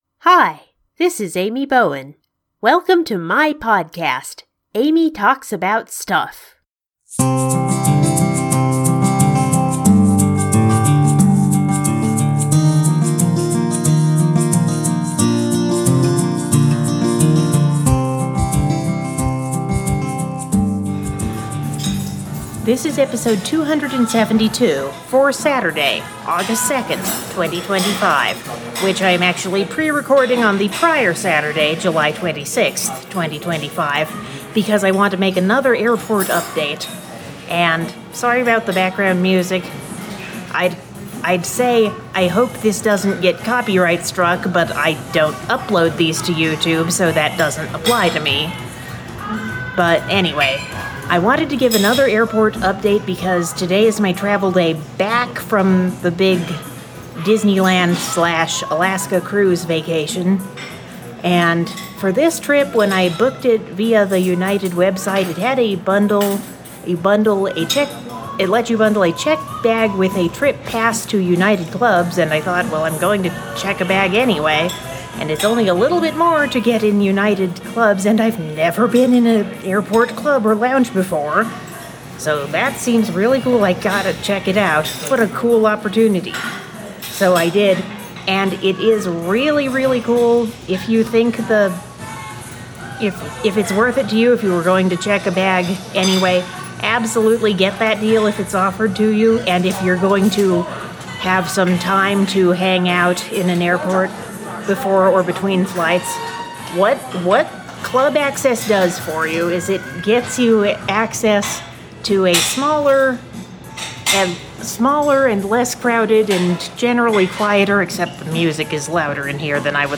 New experiences in familiar airports. Another pre-recorded episode.